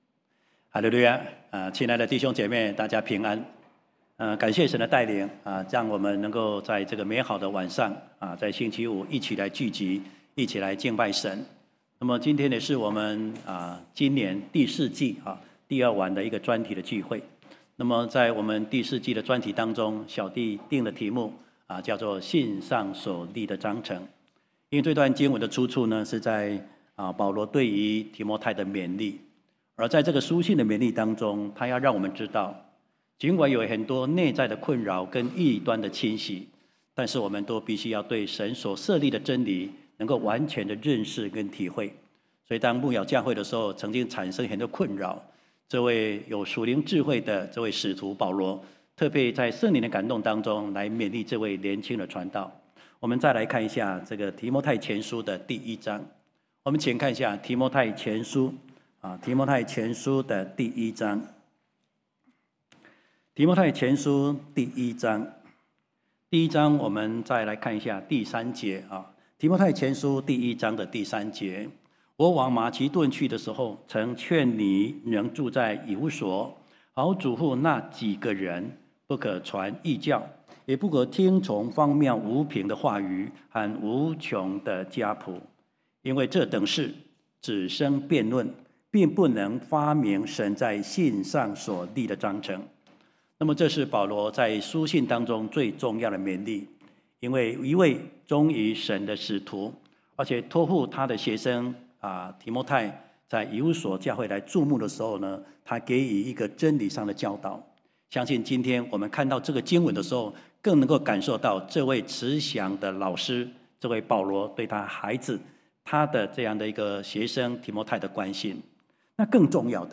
聖經專題：信上所立的章程（二）-講道錄音